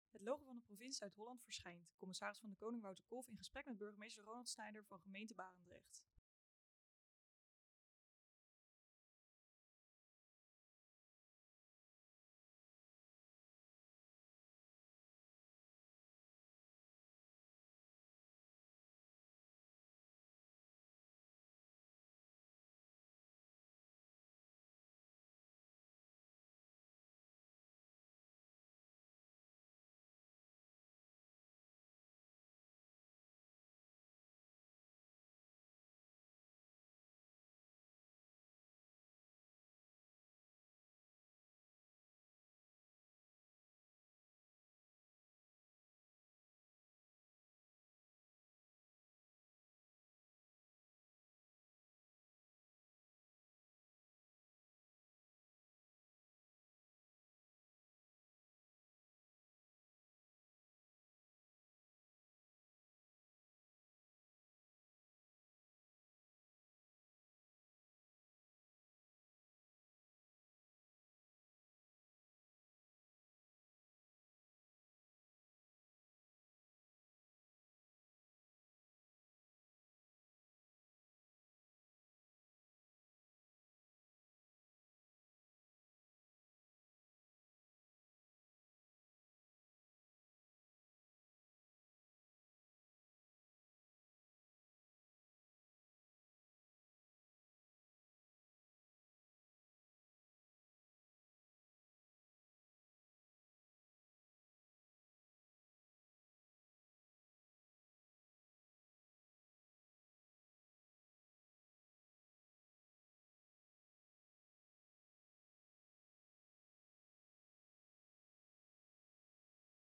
CdK in gesprek met burgemeester Barendrecht
De commissaris van de Koning bezoekt de komende tijd alle 50 gemeenten van Zuid-Holland. In deze video gaat hij in gesprek met de burgemeester van Barendrecht